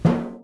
timpsnaretenor_f.wav